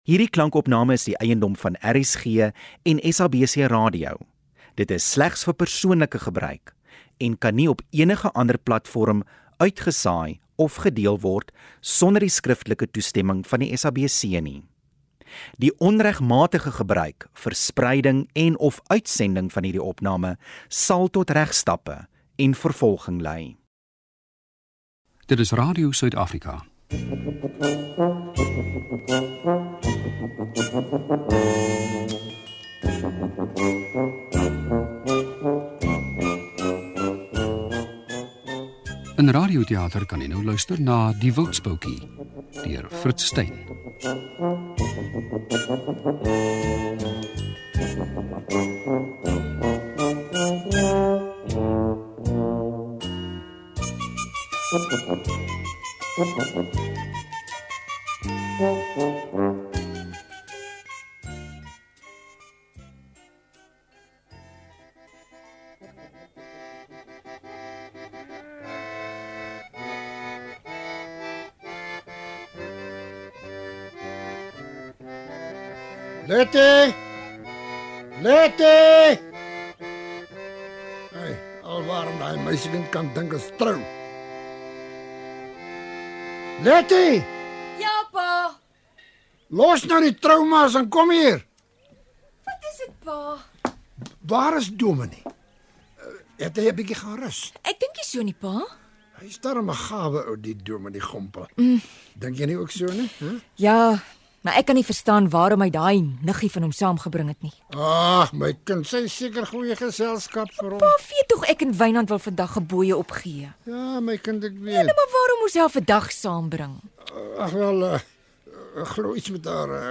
Luister weer na ons argiefdrama, Die Wildsboudjie